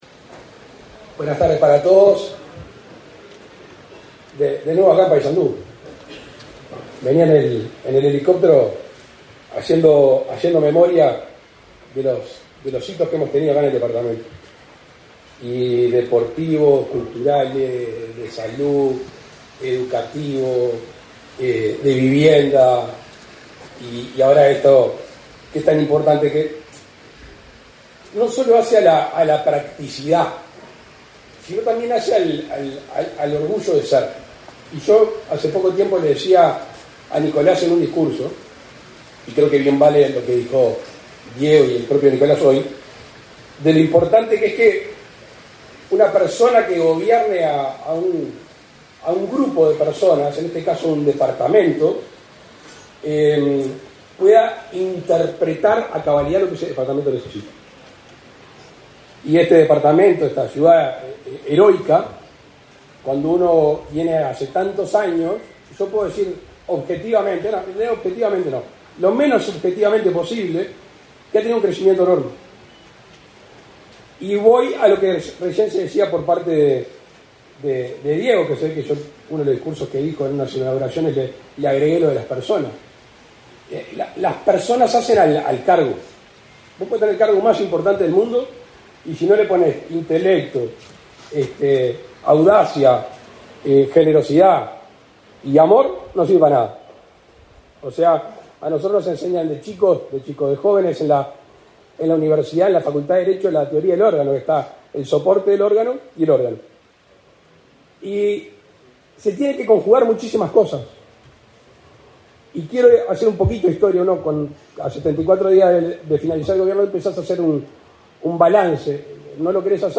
Palabras del presidente de la República, Luis Lacalle Pou
El presidente de la República, Luis Lacalle Pou, participó, este 17 de diciembre, en el acto de inauguración del aeropuerto internacional de Paysandú,